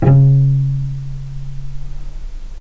healing-soundscapes/Sound Banks/HSS_OP_Pack/Strings/cello/pizz/vc_pz-C#3-pp.AIF at 48f255e0b41e8171d9280be2389d1ef0a439d660
vc_pz-C#3-pp.AIF